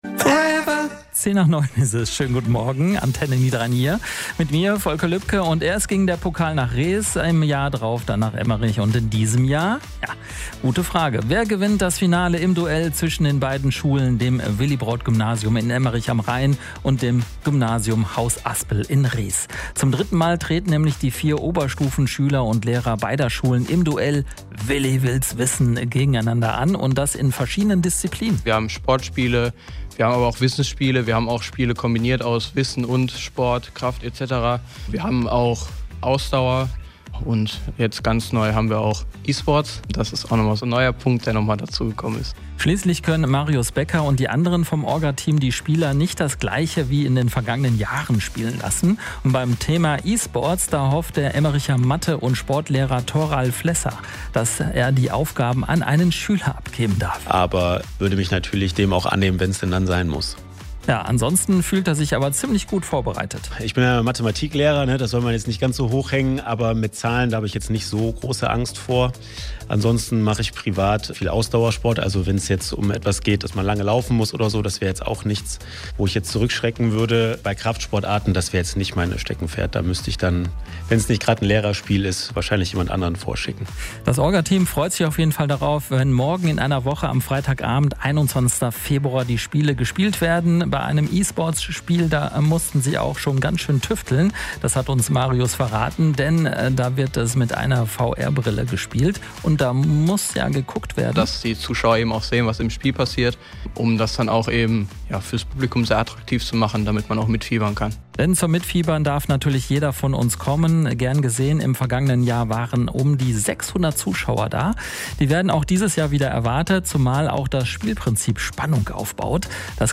Zum großen Finale von „Willi will´s Wissen“ treten am 21.02.2025 ein letzten Mal das Gymnasium Aspel in Rees gegen das Willibrord- Gymnasium Emmerich an. Wie Antenne Niederrhein von diesem kommenden Ereignis berichtet können Sie hier nachhören.